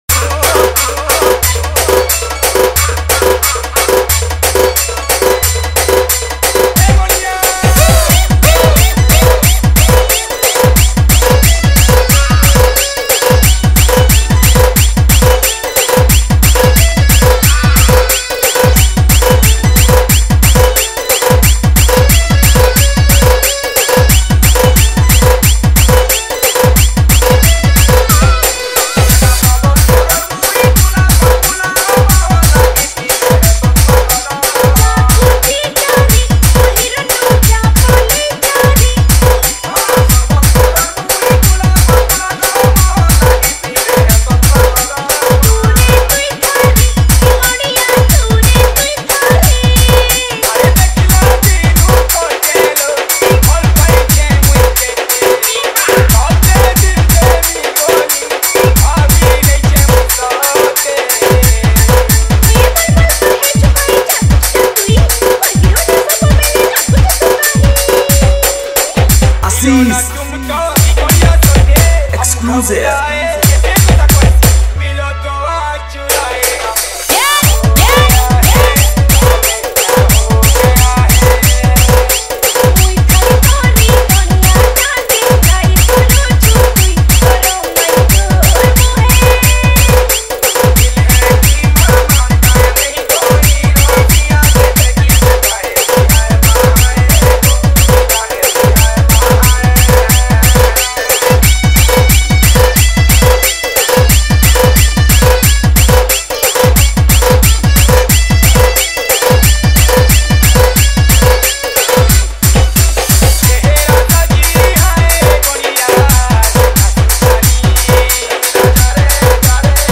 Category:  Sambalpuri Dj Song 2021